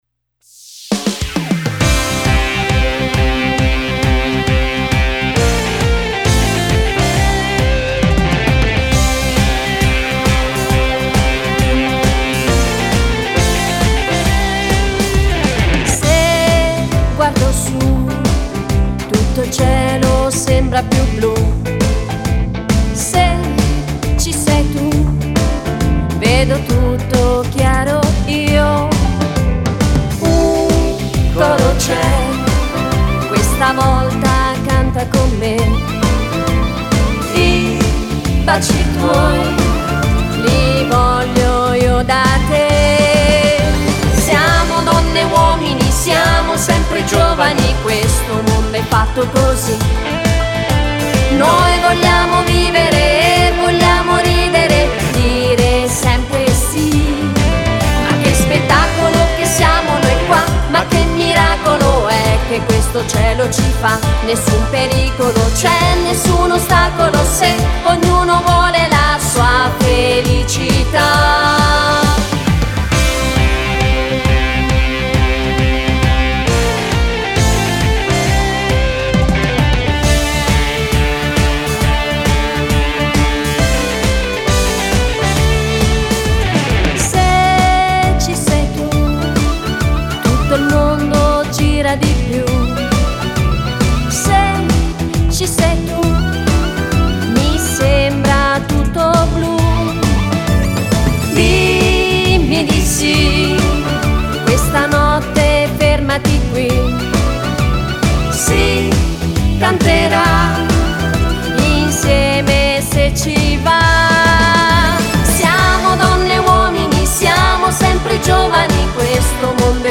Hully gully